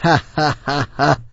l_hahahaha.wav